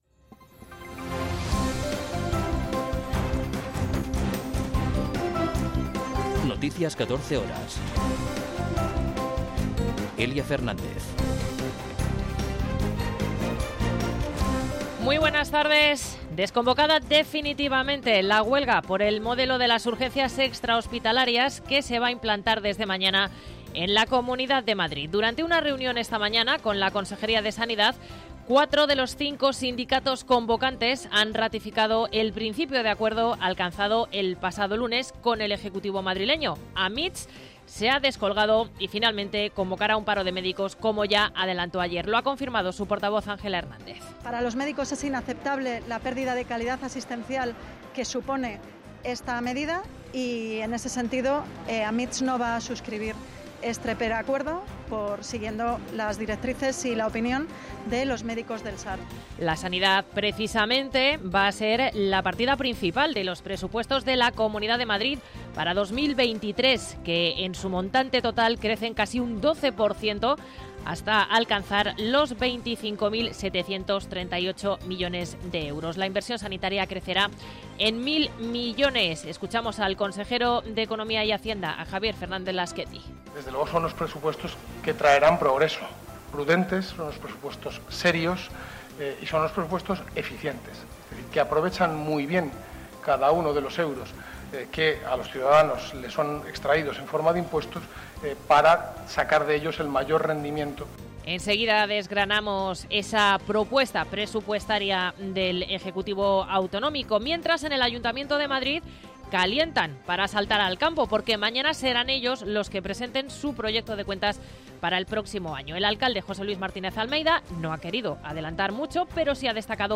Noticias 14 horas 26.10.2022